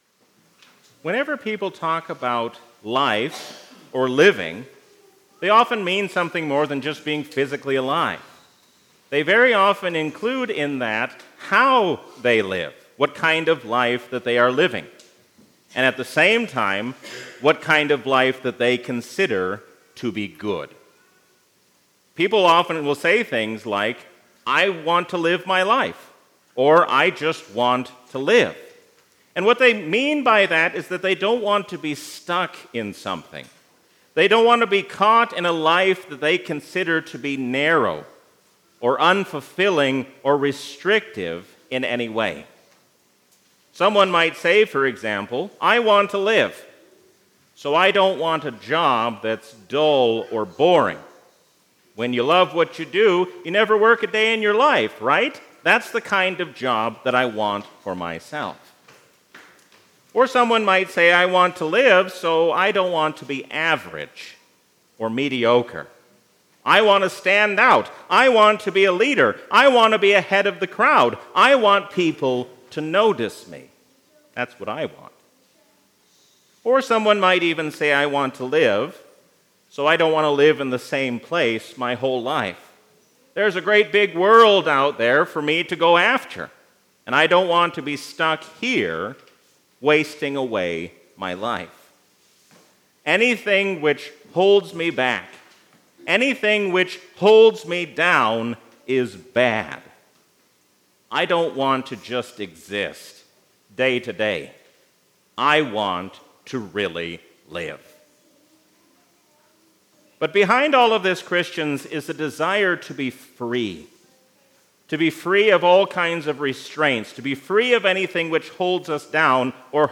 A sermon from the season "Easter 2023." Knowing who the Holy Spirit is changes how we live as Christians.